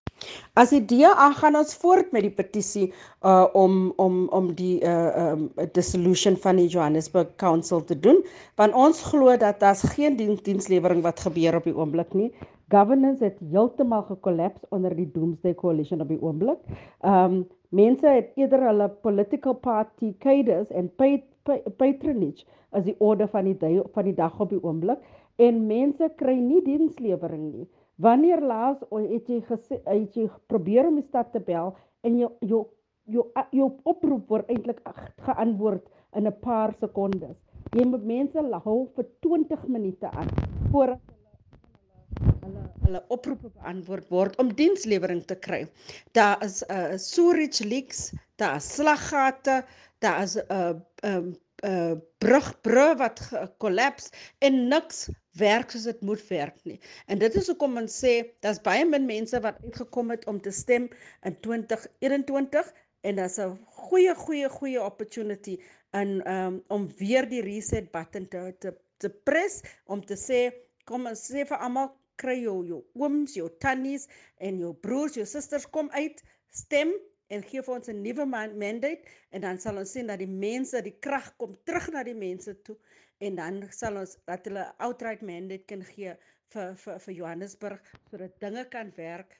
Issued by Cllr Belinda Kayser-Echeozonjoku – DA Johannesburg Caucus Leader
Note to editors: Please find a soundbite in English